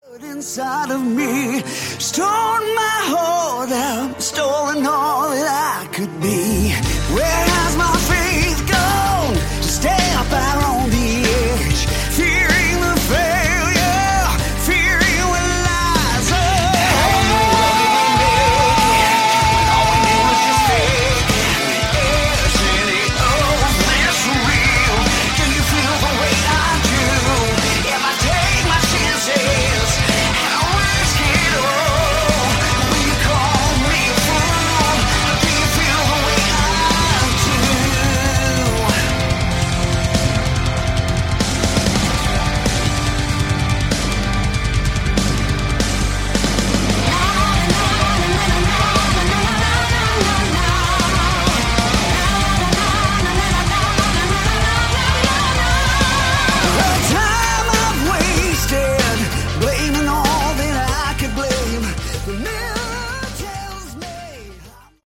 Category: Hard Rock
lead vocals, keyboards
guitars, vocals
drums
bass